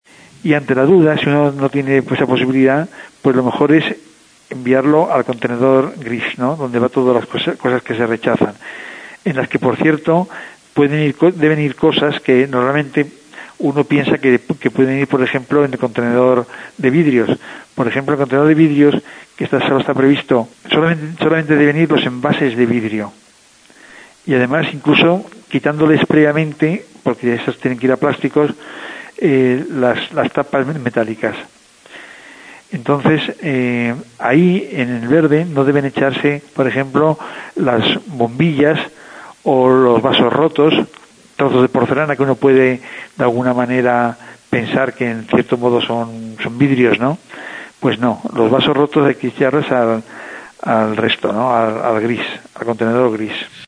Escoltem el regidor de medi ambient, José Carlos Villaro.